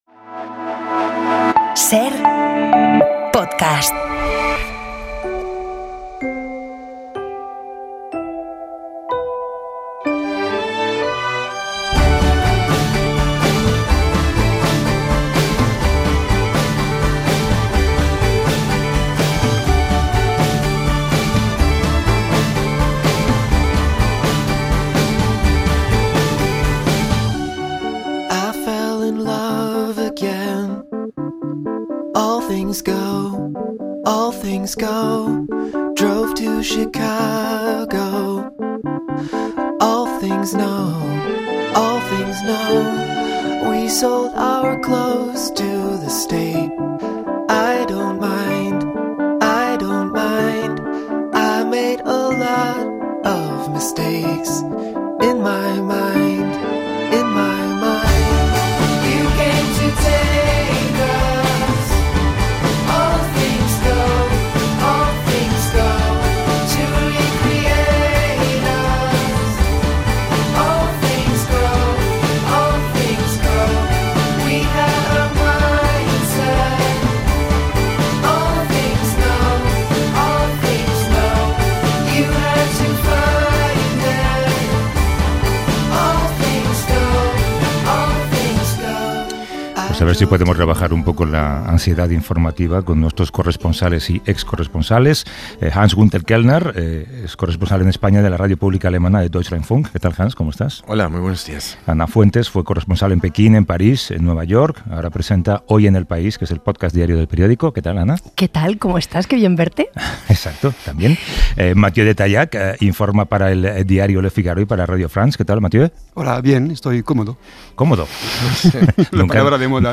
conversan con el periodista Juan Gabriel Vázquez